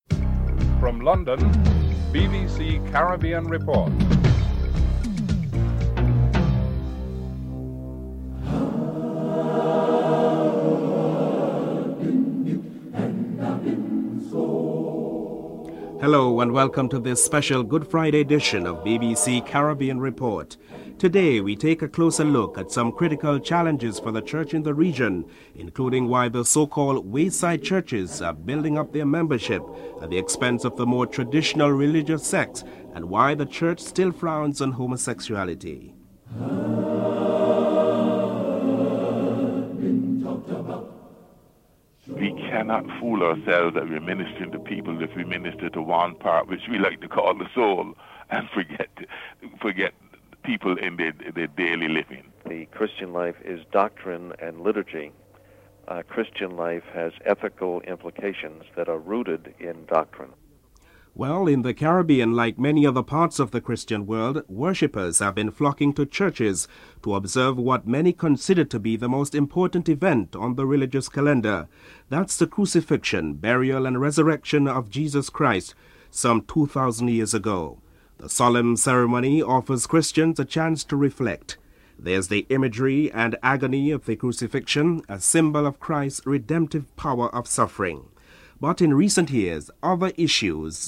In recent years other issues such as morality and church membership have been coming to the foreground in Caribbean church circles. Three prominent church leaders in the region provides insights into pressing church matters (00:42 - 01: 52)